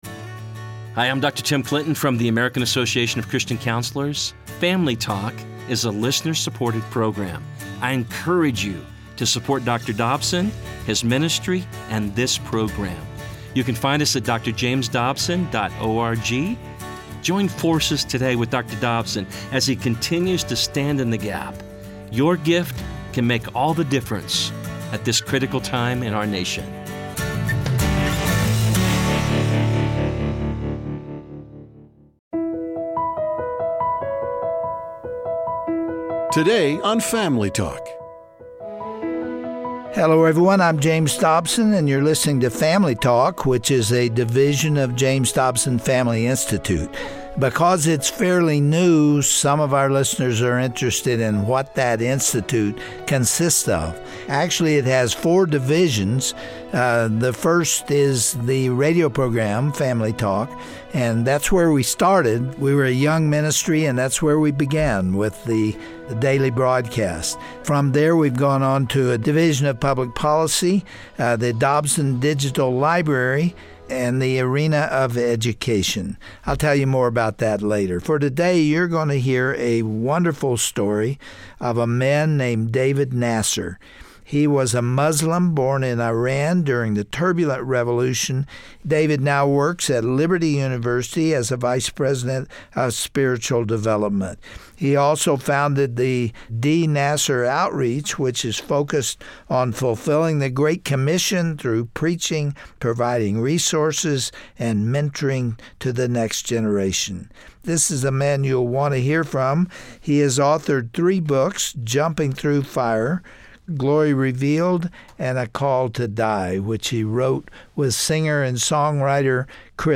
Host Dr. James Dobson
Testimony